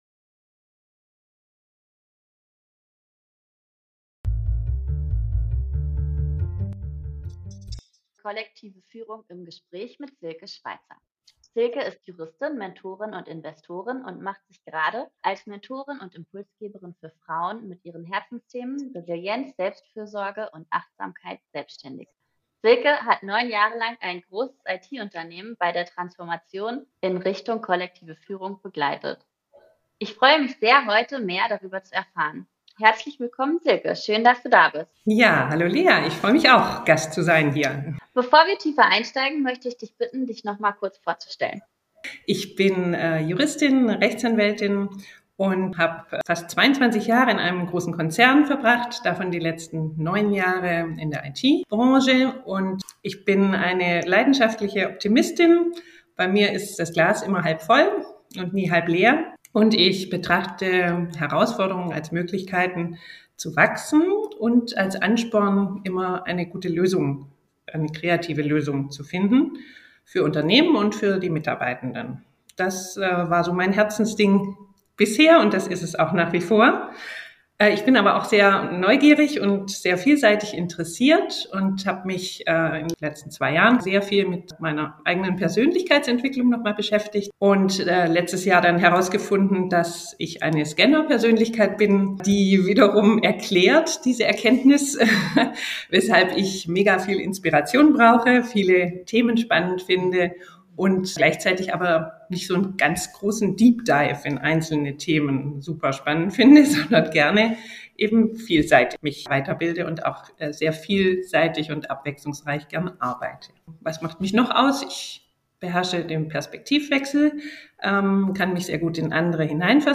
Kollektive Führung im Gespräch